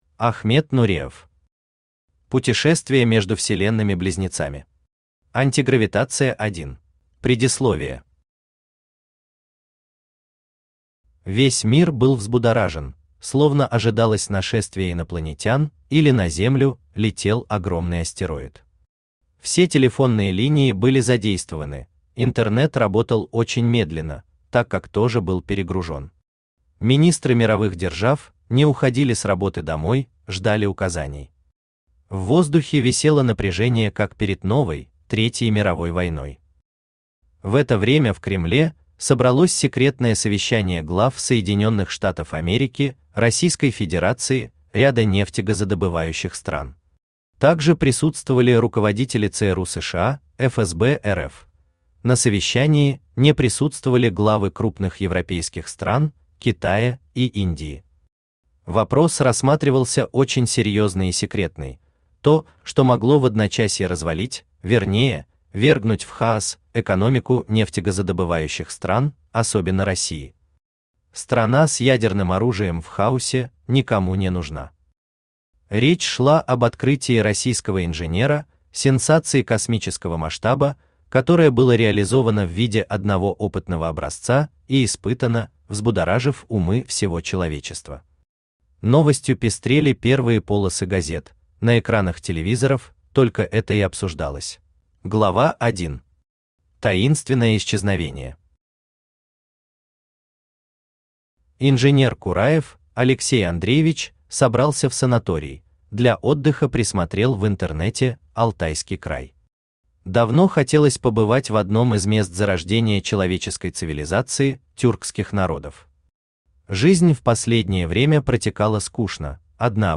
Аудиокнига Путешествие между Вселенными-Близнецами. Антигравитация 1 | Библиотека аудиокниг
Антигравитация 1 Автор Ахмед Нуреев Читает аудиокнигу Авточтец ЛитРес.